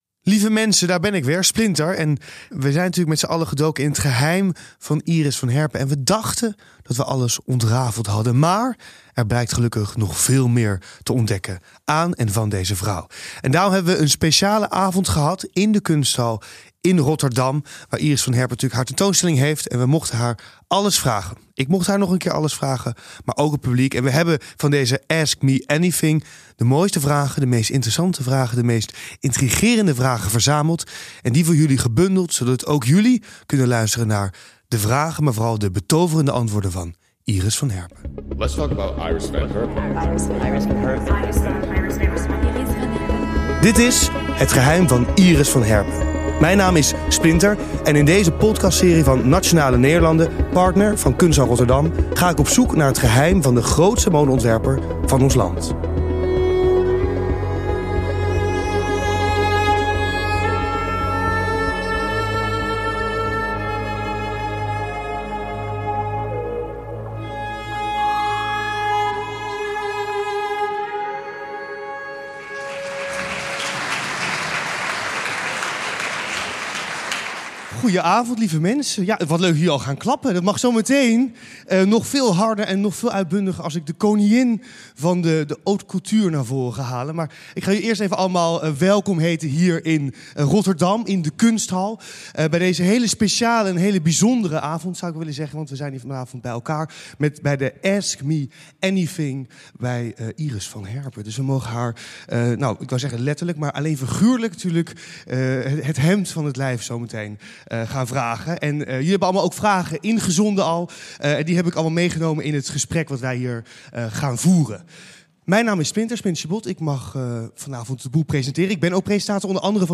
Onder leiding van Splinter Chabot kregen fans de unieke kans om hun vragen te stellen aan mode-icoon Iris van Herpen. In deze bonusaflevering deelt zij nog meer over haar creatieve proces, inspiratiebronnen en haar werk voor sterren als Beyoncé en Lady Gaga.